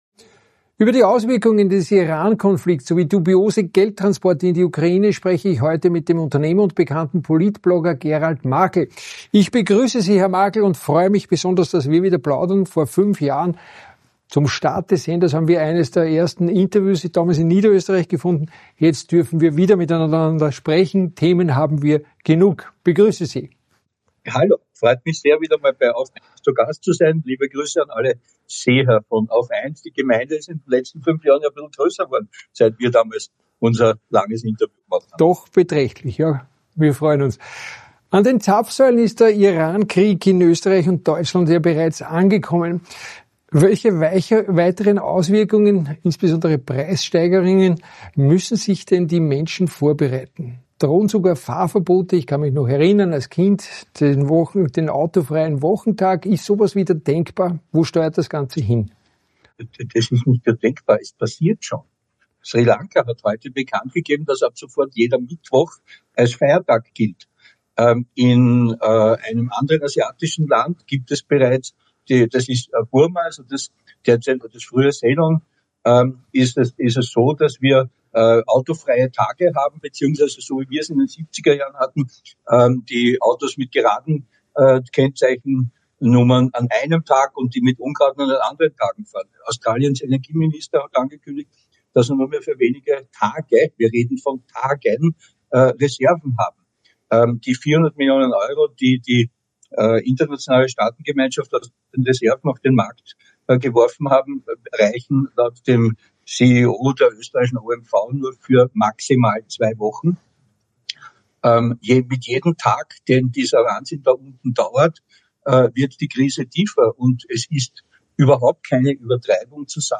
An den Zapfsäulen in Deutschland und Österreich ist der Iran-Krieg bereits angekommen. Doch was droht neben den Preiserhöhungen noch? Brisantes Wirtschafts-Interview